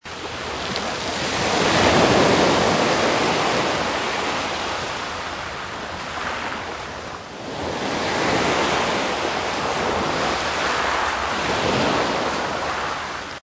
Ambient
snd_ab_sea.mp3